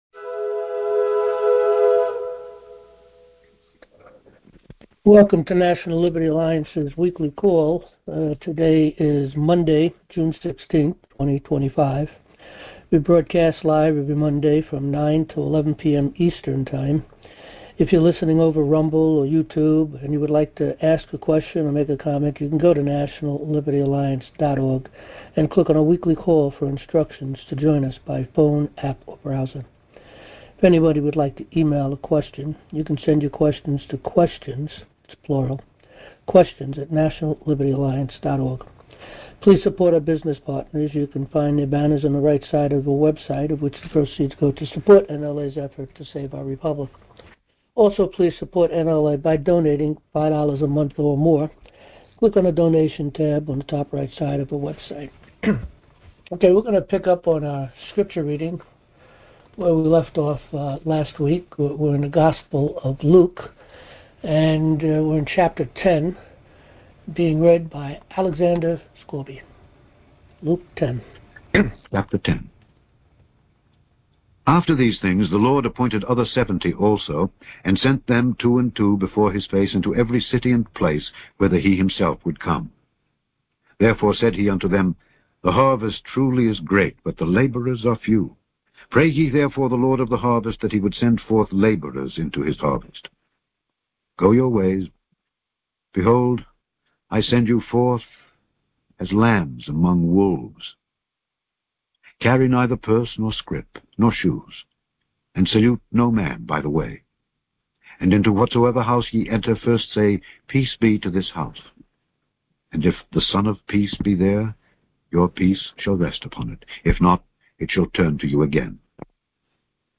Join our Live Monday Night Open Forum | National Liberty Alliance